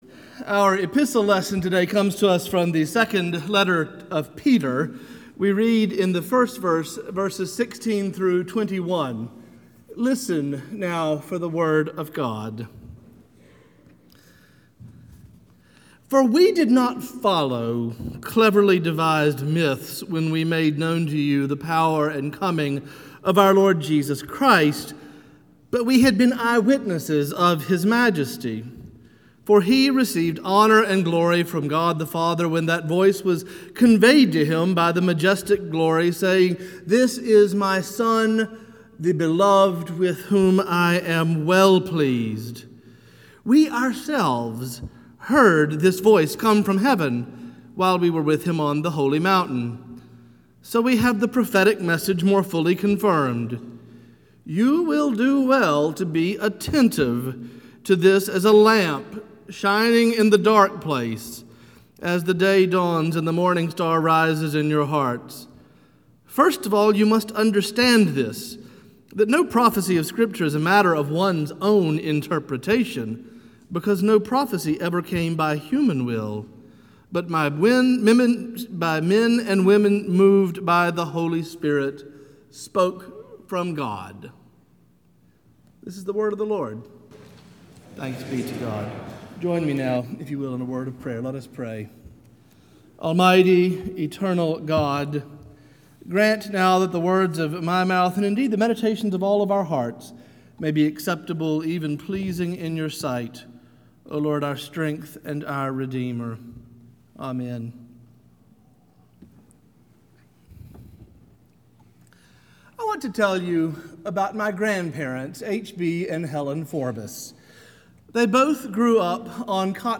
Morningside Presbyterian Church - Atlanta, GA: Sermons: Eyewitnesses
Morningside Presbyterian Church - Atlanta, GA